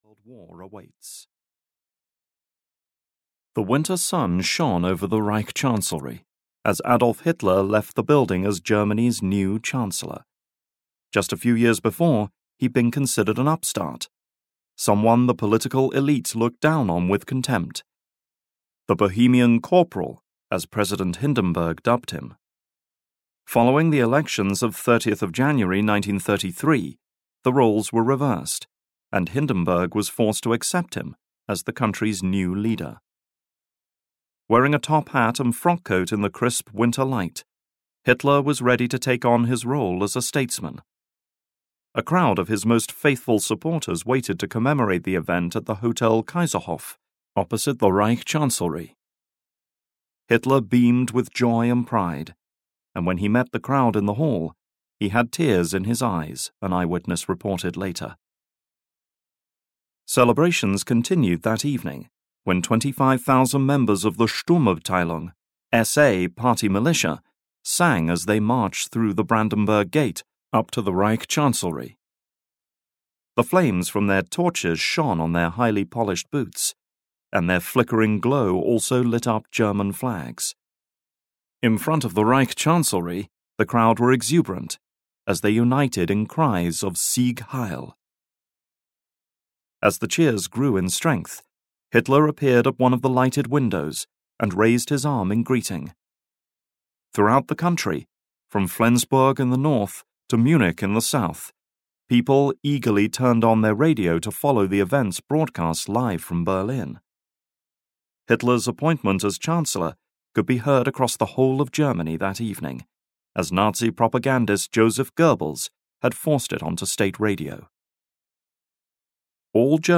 The Third Reich (EN) audiokniha
Ukázka z knihy